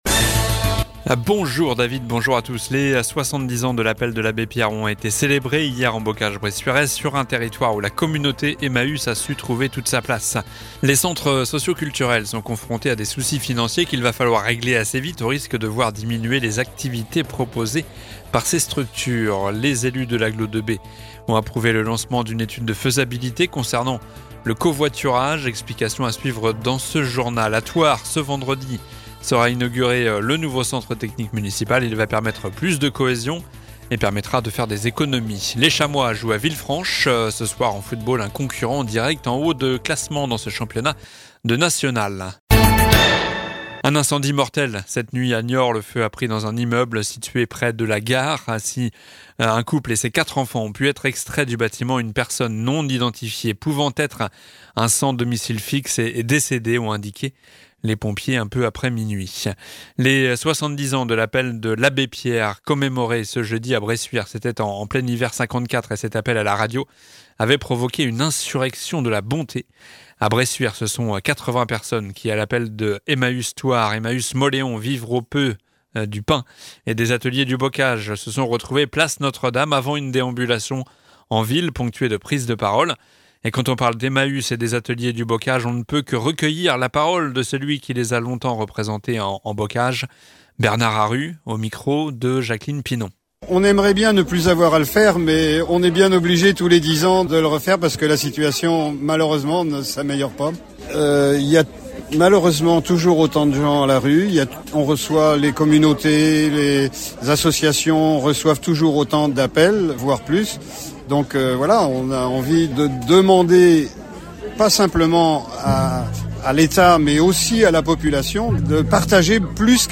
Journal du vendredi 02 février (midi)